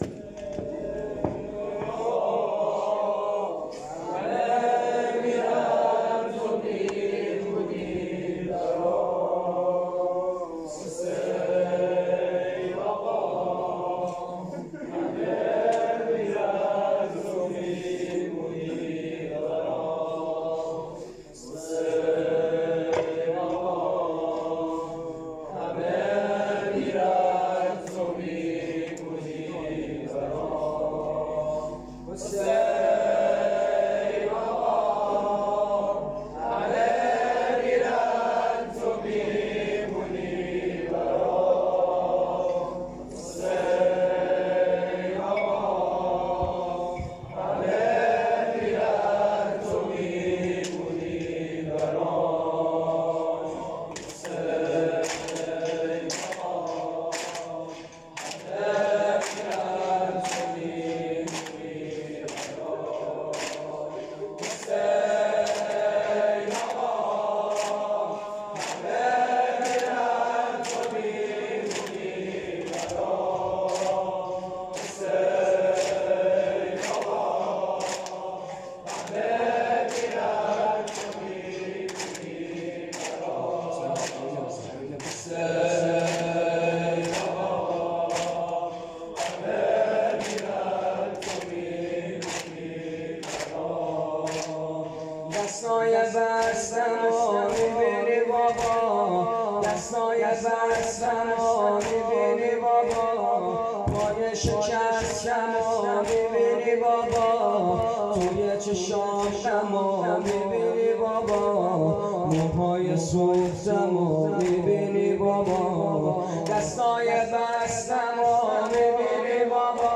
مداحی حضرت رقیه سلام الله علیه